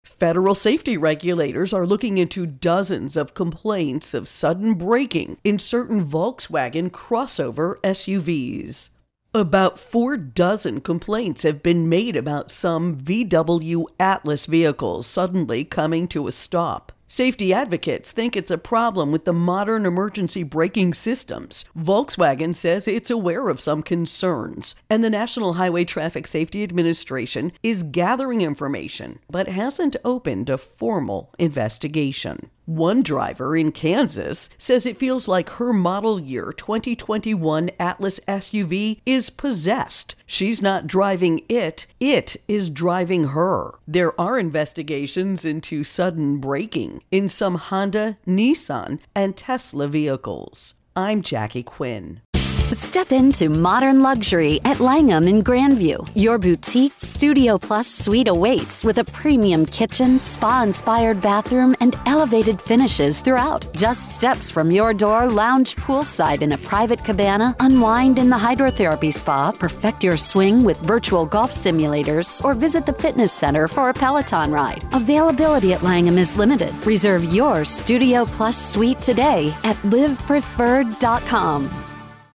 Volkswagen Mystery Braking Intro and Voicer